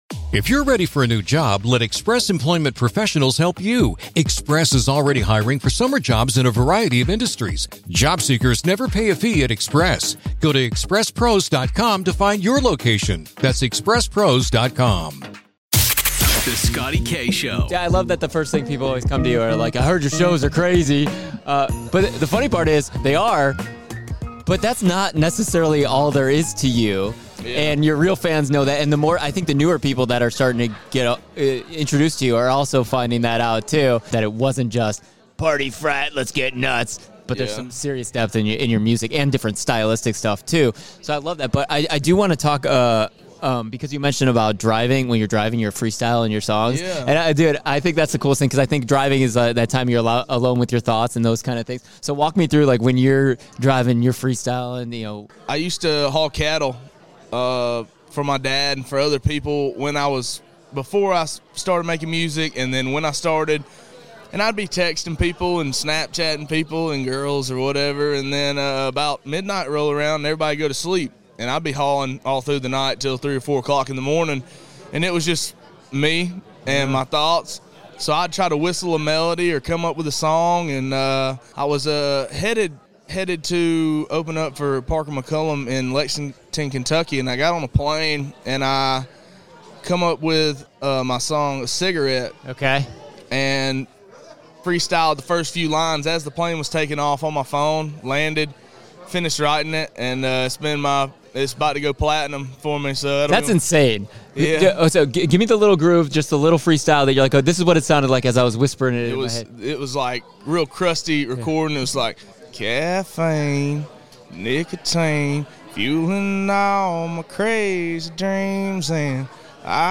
Interview ACM's 2025